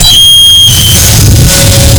sonarPingWaterVeryClose2.ogg